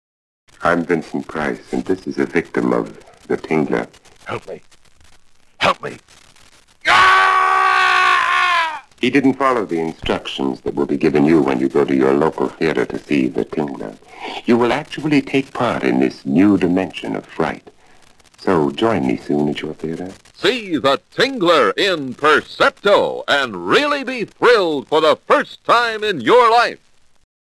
10, 20, 30, and 60 second radio spots
The-Tingler-Vincent-Price-30-converted.mp3